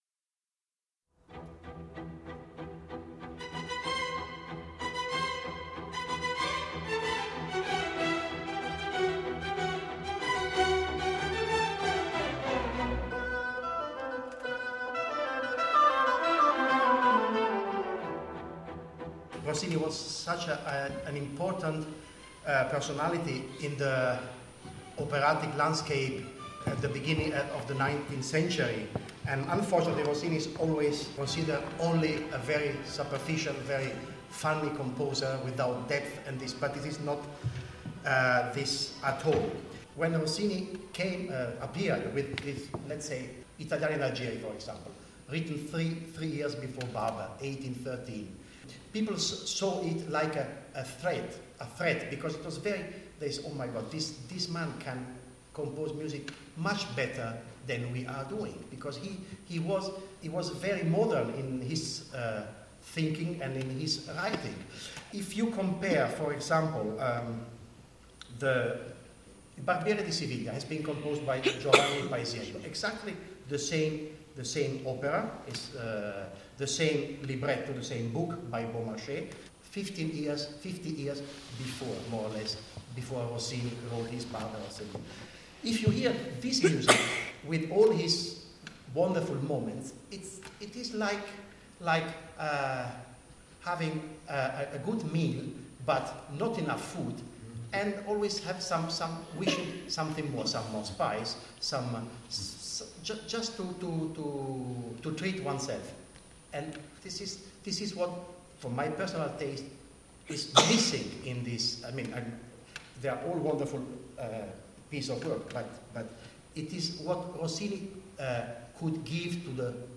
talks about Rossini (recorded live)
Scottish Opera Production Studios on Monday 17 October 2011.